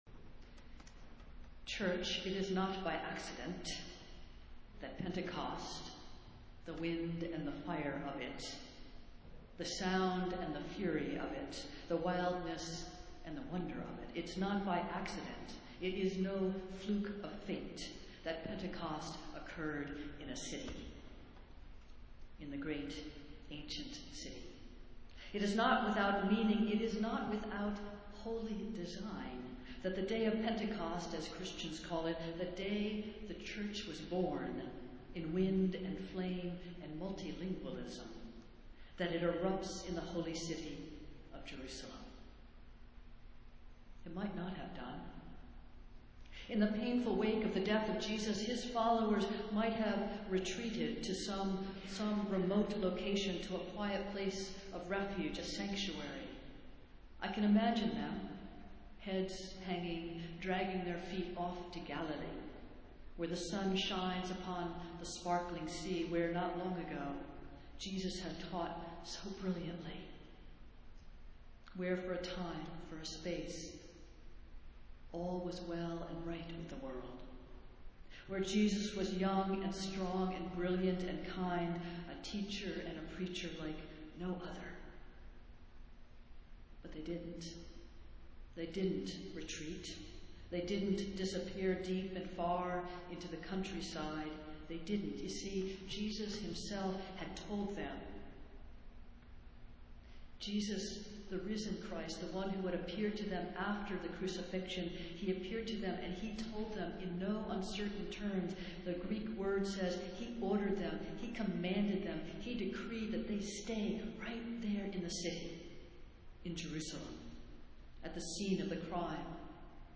Festival Worship - Pentecost Sunday